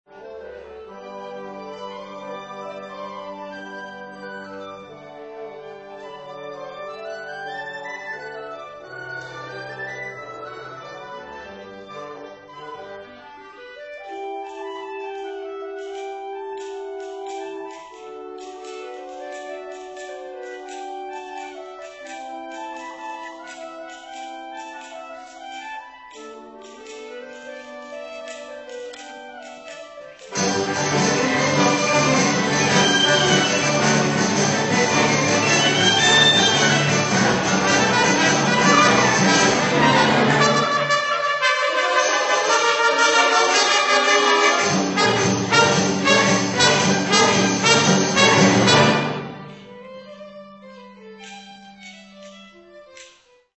Concert on Sunday 25th June 2000
Peel Hall, Salford University